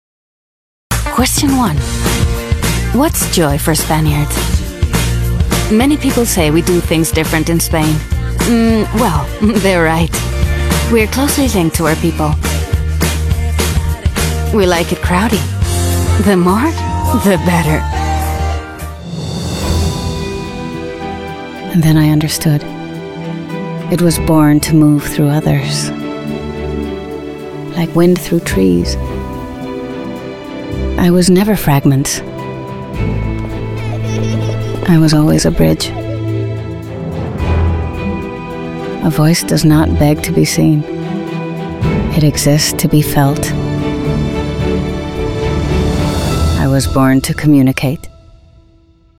Versatile, Elegant, Sincere, Warm tones. 30-40.
English showreel
English Conversational, Bright, Upbeat, Natural